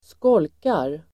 Ladda ner uttalet
Uttal: [²sk'ål:kar]
skolkar.mp3